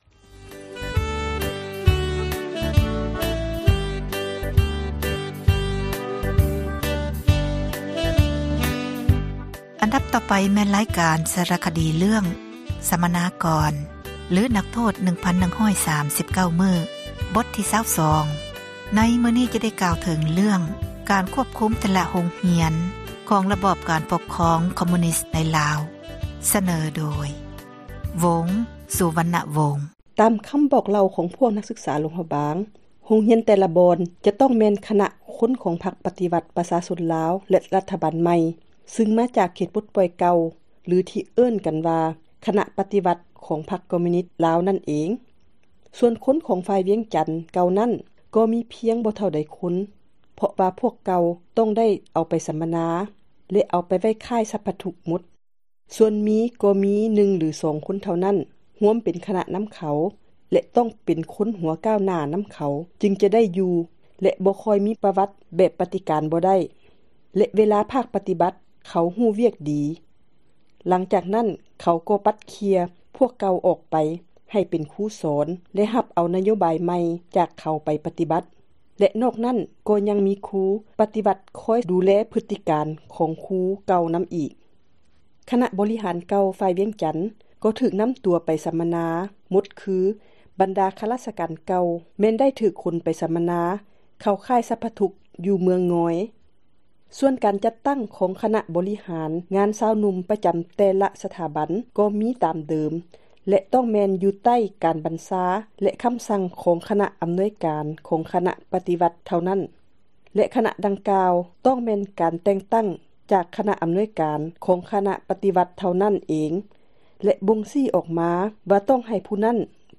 ສາຣະຄະດີ ເຣື້ອງ ສັມມະນາກອນ ຫຼື ນັກໂທດ 1139 ມື້, ບົດທີ 22 ນີ້ ຈະກ່າວເຖິງ ການຄວບຄຸມ ແຕ່ລະໂຮງຮຽນ ຂອງ ຣະບອບ ການປົກຄອງ ຄອມມຸຍນິສ ໃນລາວ.